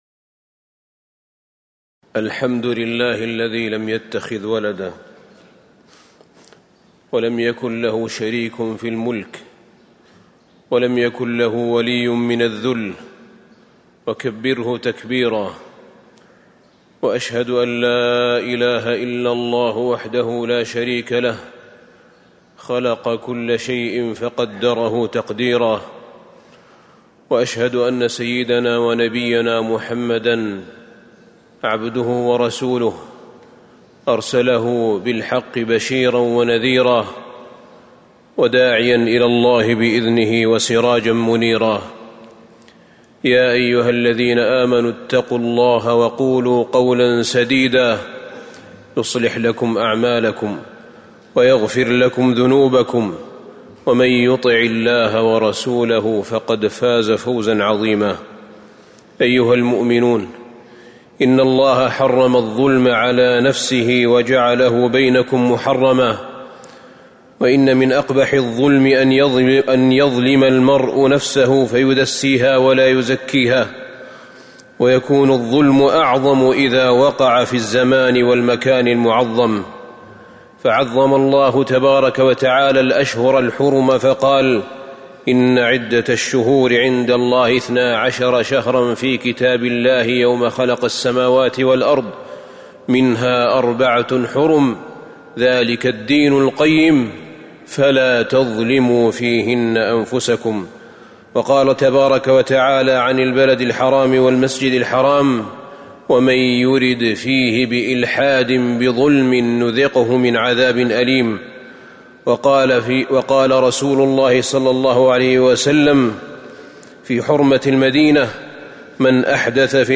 تاريخ النشر ٧ محرم ١٤٤٤ هـ المكان: المسجد النبوي الشيخ: فضيلة الشيخ أحمد بن طالب بن حميد فضيلة الشيخ أحمد بن طالب بن حميد شهر الله المحرم